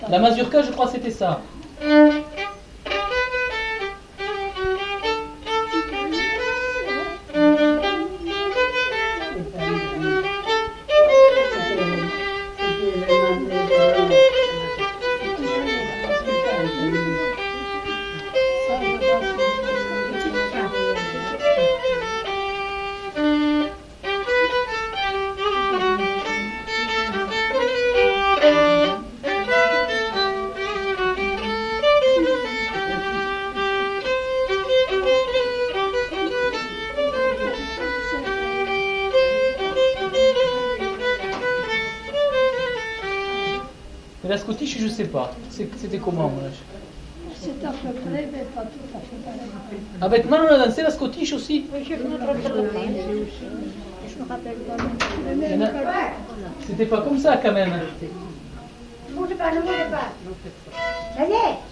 Aire culturelle : Couserans
Lieu : Castillon-en-Couserans
Genre : morceau instrumental
Instrument de musique : violon
Danse : mazurka